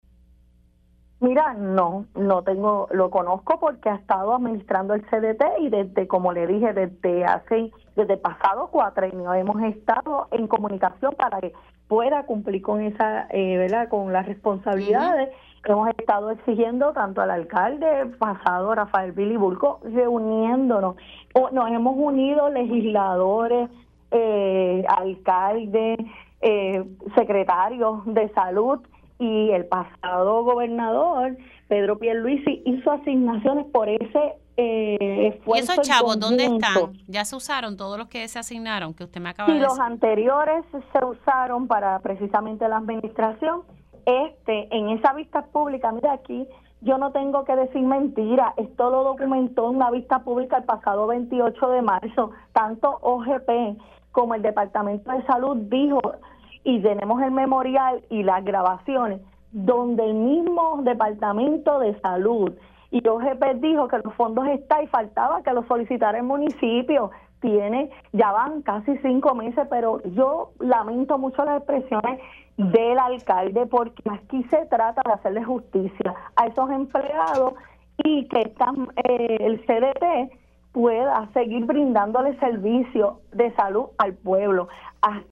420-ESTRELLA-MARTINEZ-REP-PPD-ALCALDE-DEBE-SOLICITAR-FONDOS-ADICIONALES-PARA-CDT-APROBADOS-.mp3